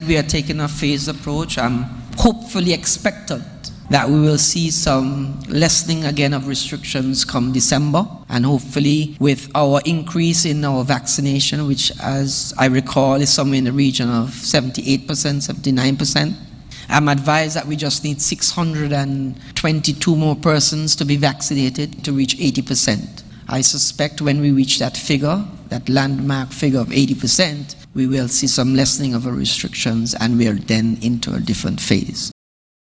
Federal Minister of Tourism, Lindsay Grant, expressed his anticipations for the 2021-2022 Cruise Season in a Covid-19 environment, during a press conference on 19th Nov.
Minister of Tourism, Lindsay Grant.